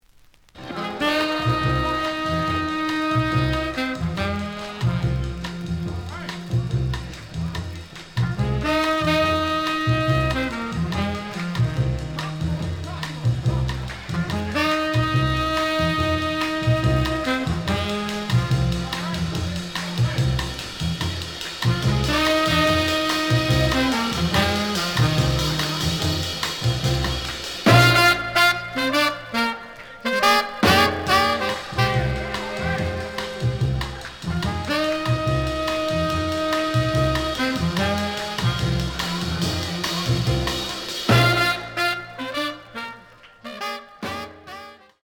The audio sample is recorded from the actual item.
●Genre: Latin Jazz